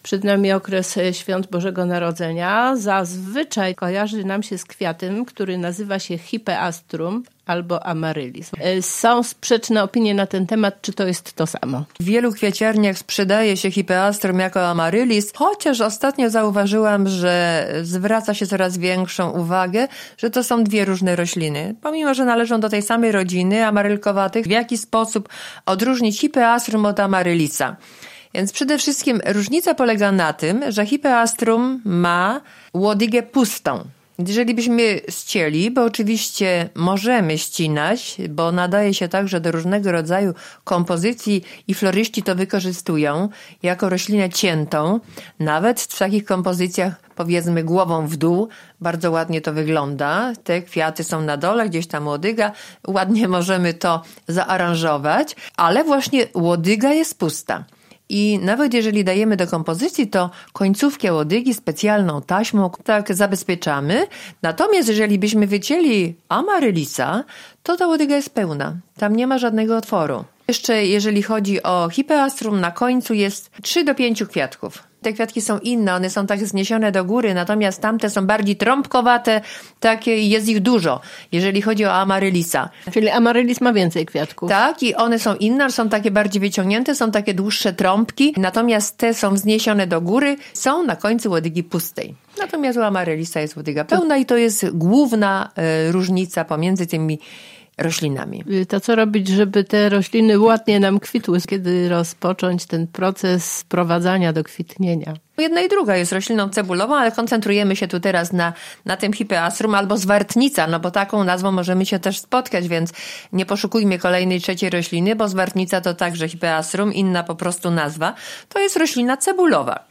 O tym oraz o sposobach pielęgnacji można posłuchać w rozmowie.